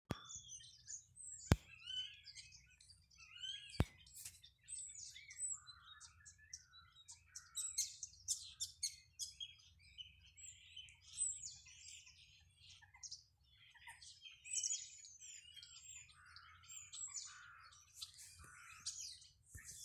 Mājas strazds, Sturnus vulgaris
Skaits10 - 16
StatussDzied ligzdošanai piemērotā biotopā (D)
PiezīmesDzied kokos, kuros ierīkoti būrīši